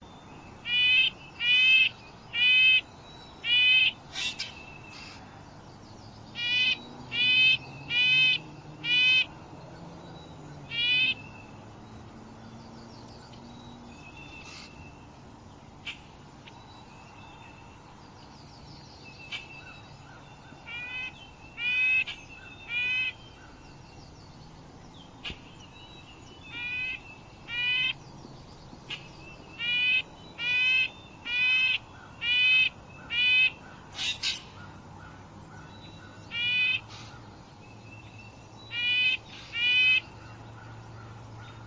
Starting in the early fall and into the early spring, on Ocracoke you may hear a sound reminiscent of a tin horn (yank, yank) coming from a tree, usually a long-leaf pine.
red-breasted-nuthatch-recording-10496.mp3